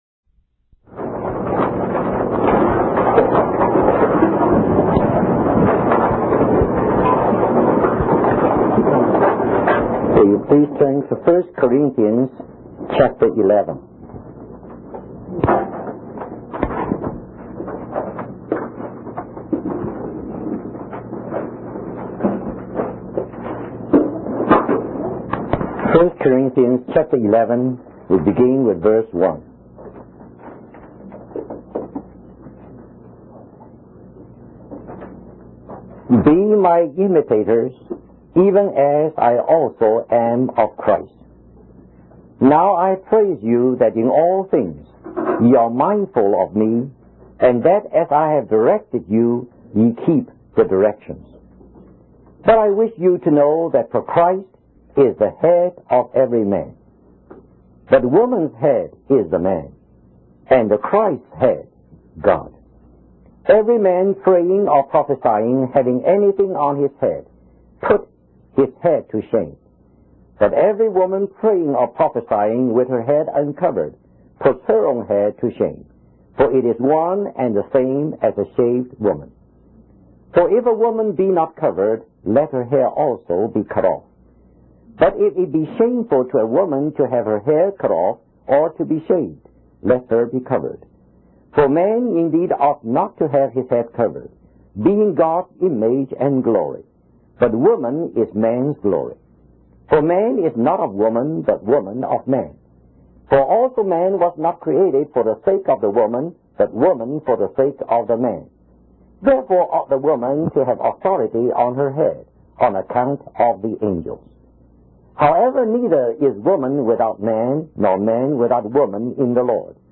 In this sermon, the speaker emphasizes the importance of divine order in fulfilling God's purpose. The Son of God willingly took on the form of a man and accepted God as his head, demonstrating humility and obedience. The speaker highlights how Jesus resisted temptation and relied on God's word for sustenance, rather than using his power for personal gain.